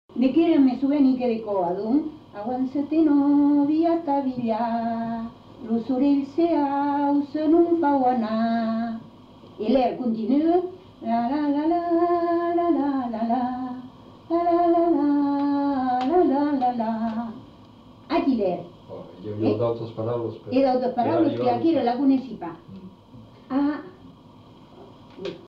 Aire culturelle : Marmandais gascon
Genre : chant
Effectif : 1
Type de voix : voix de femme
Production du son : chanté ; fredonné